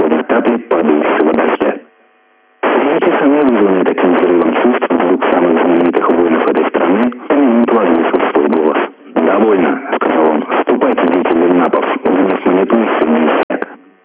This one shows an OIRT station from Russia (well, there actually were two small Sporadic-E openings into that direction yesterday early noon) wiping out about 70 kHz of spectrum with it’s wideband FM signal:
While it sounds a bit distorted and certainly not WFM quality 😉 it is still intelligible.
The broader the WFM signal the less the intelligibility, of course: